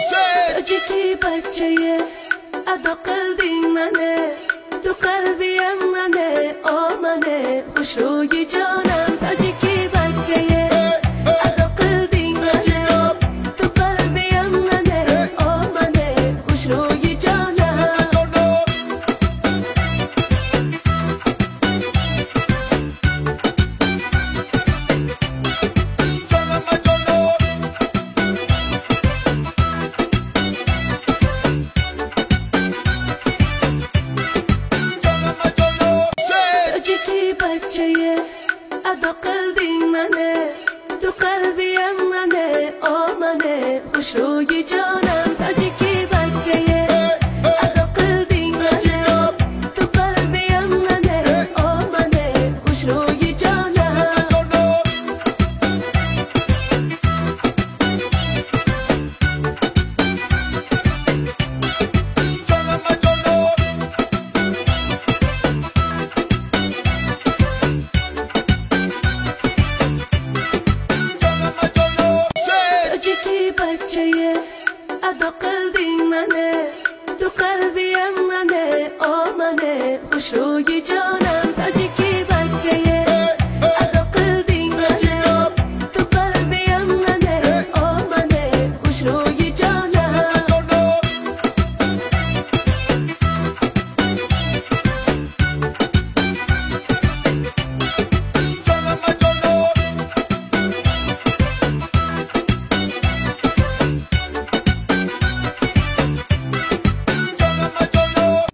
Качество: 320 kbps, stereo
Узбекская музыка